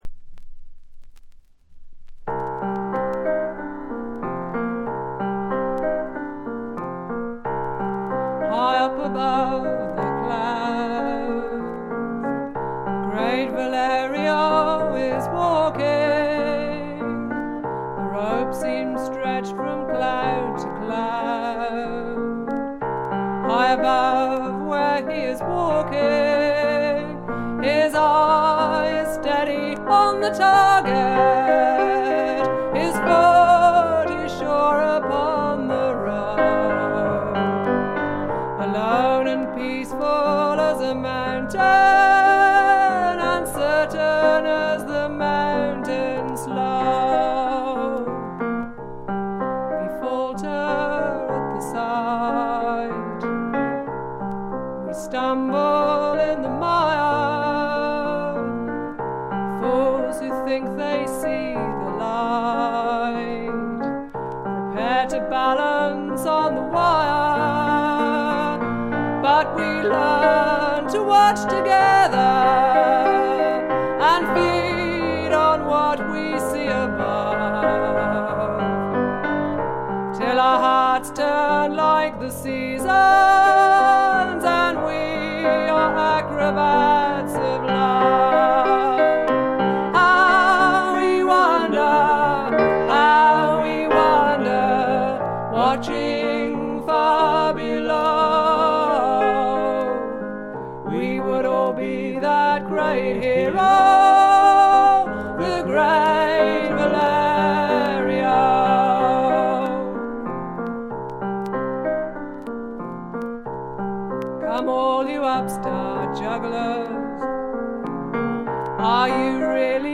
英国の女性フォークシンガー／ギタリスト。
味のあるアルとト・ヴォイスで淡々と歌っていクールなものです。
試聴曲は現品からの取り込み音源です。
Vocals, Guitar, Electric Bass, Banjo